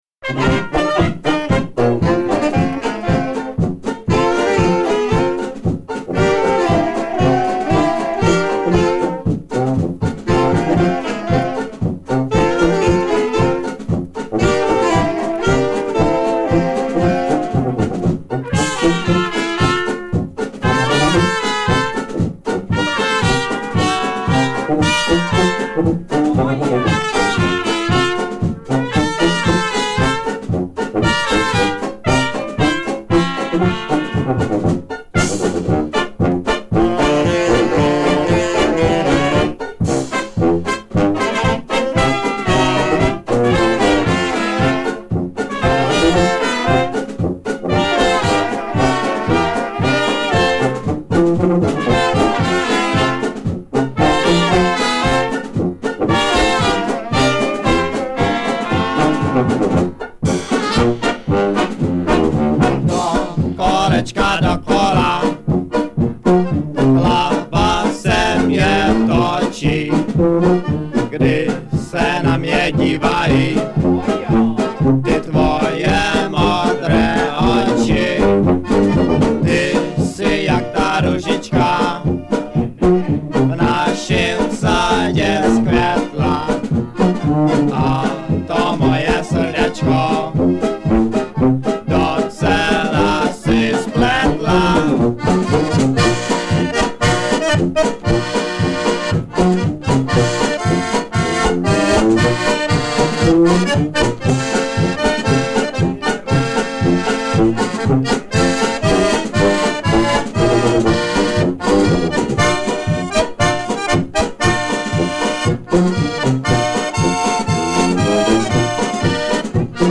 Commentary 8.